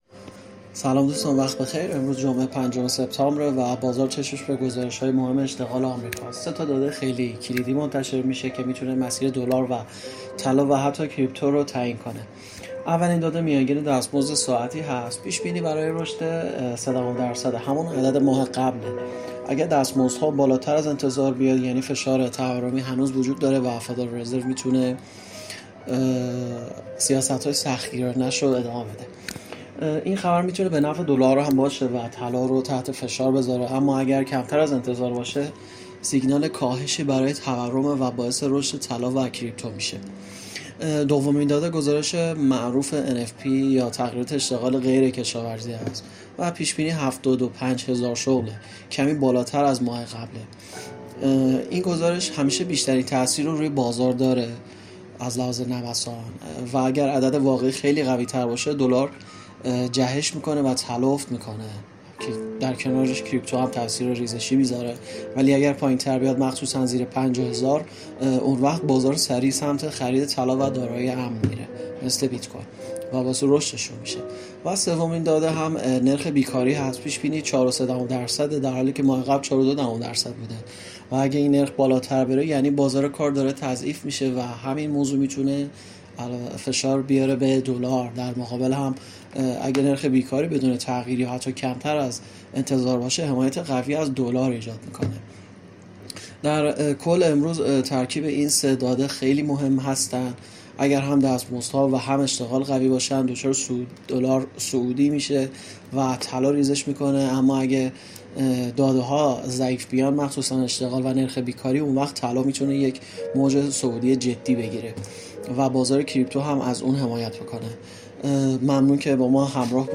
🔸گروه مالی و تحلیلی ایگل با تحلیل‌های صوتی روزانه در خدمت شماست! هدف اصلی این بخش، ارائه تحلیلی جامع و دقیق از مهم‌ترین اخبار اقتصادی و تأثیرات آن‌ها بر بازارهای مالی است.